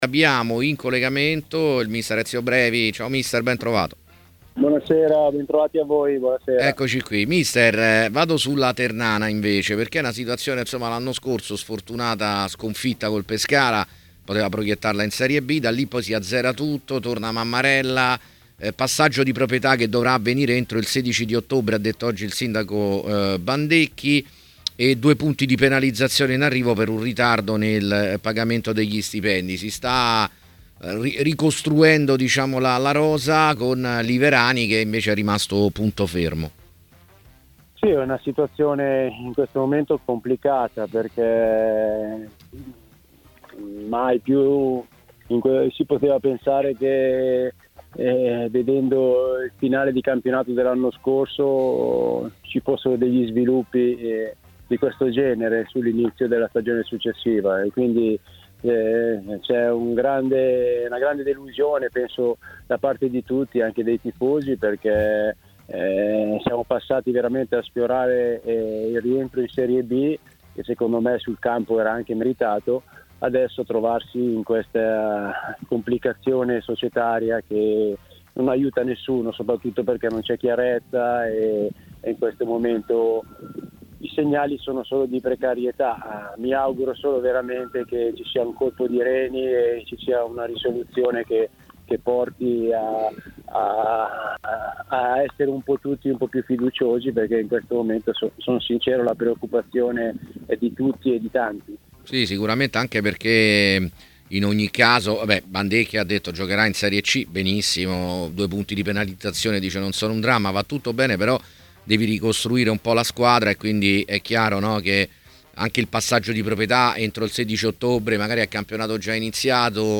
Ai microfoni di TMW Radio, nel corso di A Tutta C